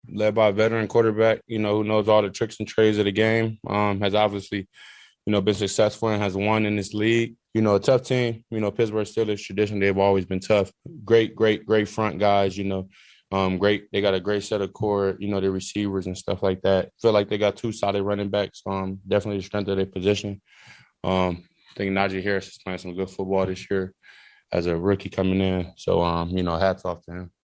Chiefs defensive end Frank Clark says the Steelers are a good team.